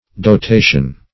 Dotation \Do*ta"tion\, n. [LL. dotatio, fr. L. dotare to endow,